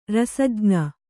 ♪ rasajña